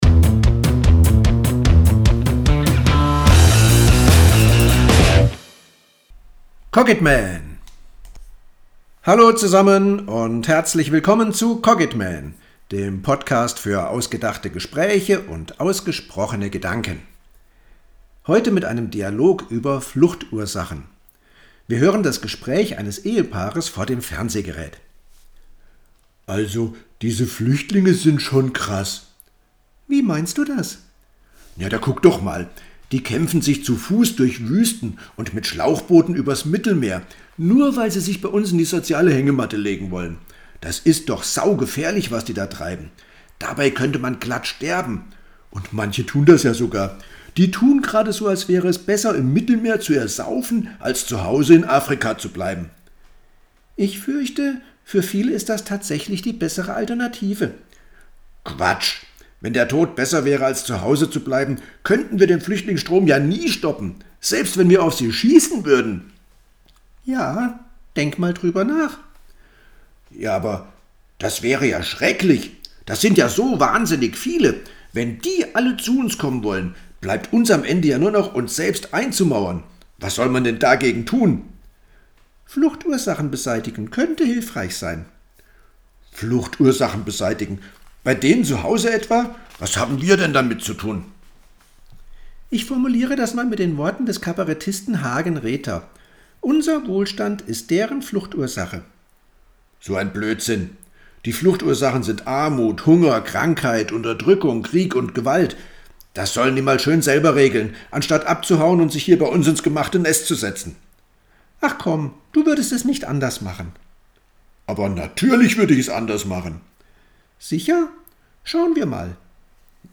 Dialog_Fluchtursachen.mp3